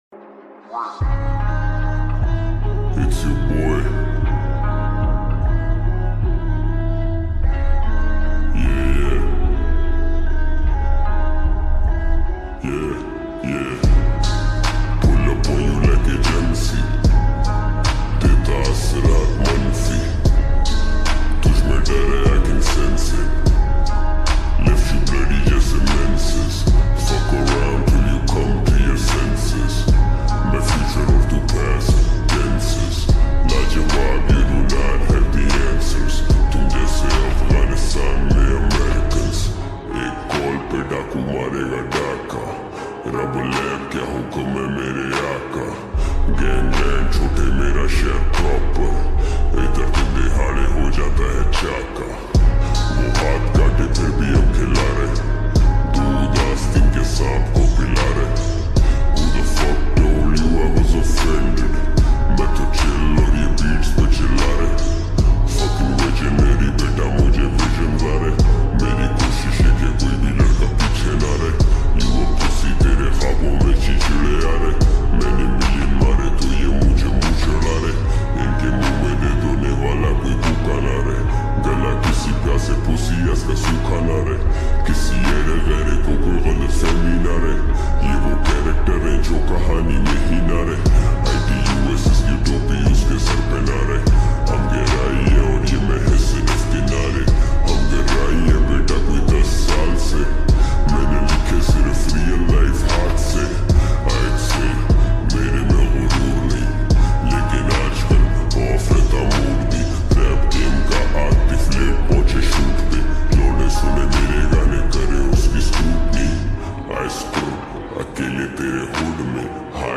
Slowed x Reverb